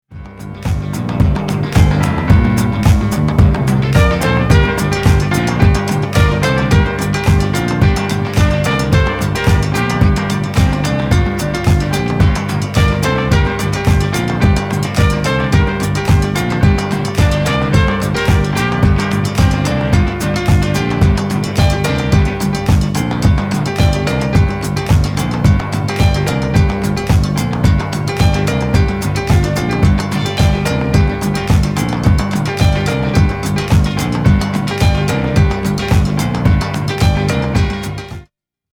黒くロウなドラムにベース、そしてピアノ、トランペットが絡み
ジワジワ腰にくる、完全フロア対応なイントロ長め人力４つ打ち